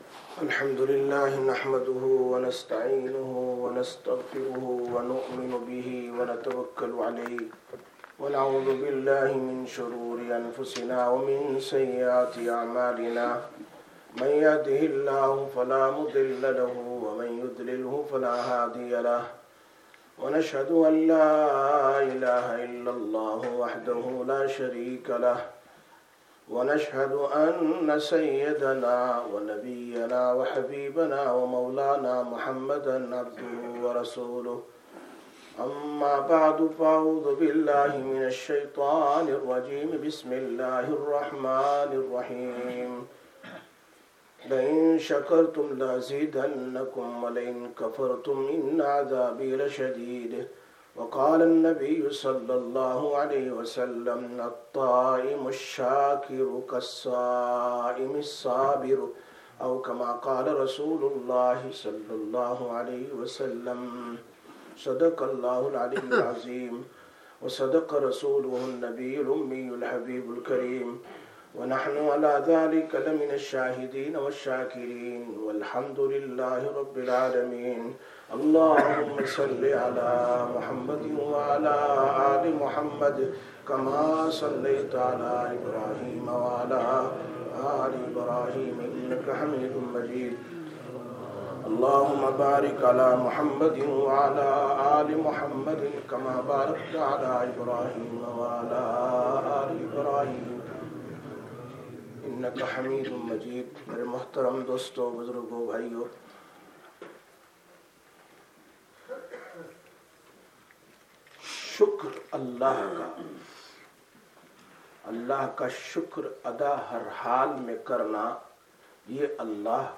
24/04/2026 Jumma Bayan, Masjid Quba